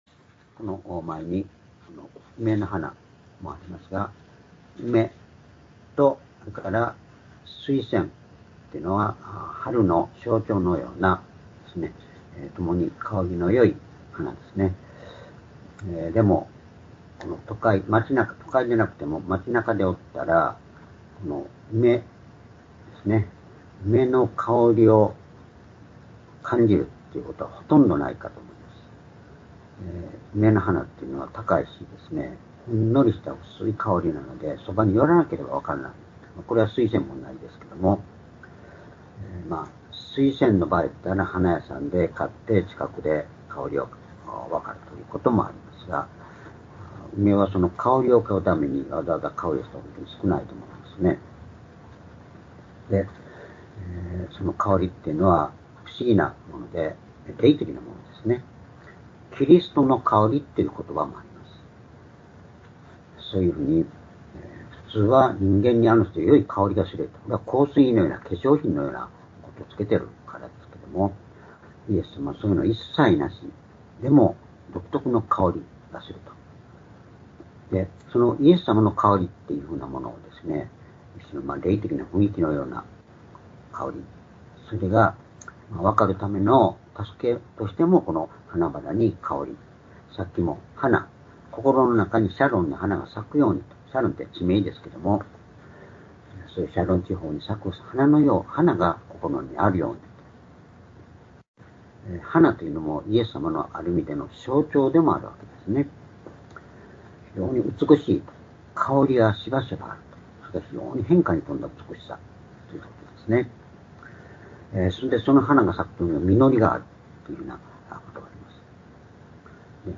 主日礼拝日時 2025年3月9日(主日礼拝) 聖書講話箇所 「主からの愛を覚え続ける人たち」 ヨハネ２０章１～４節 ※視聴できない場合は をクリックしてください。